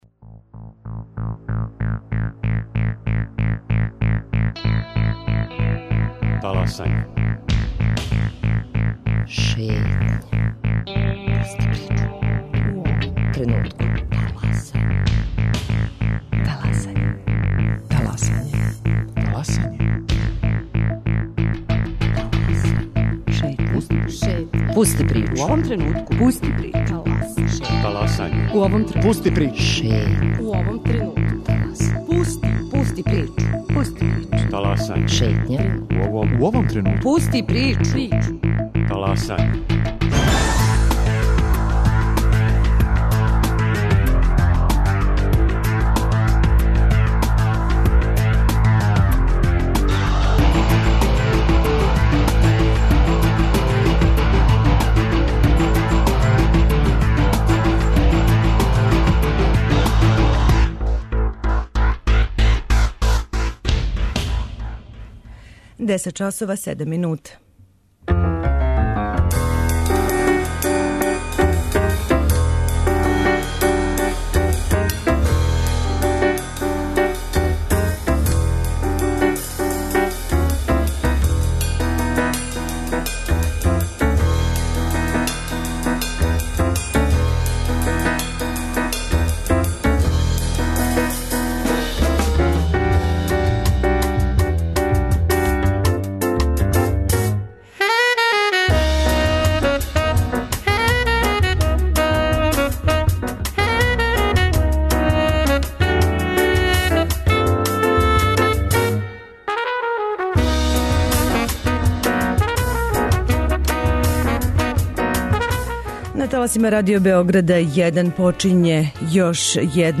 У првом делу имамо две гошће захваљујући којима ћете упознати једно ново занимање и једну младу, а важну институцију у нашој земљи.